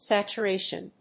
音節sat・u・ra・tion 発音記号・読み方
/s`ætʃʊréɪʃən(米国英語), ˌsætʃɜ:ˈeɪʃʌn(英国英語)/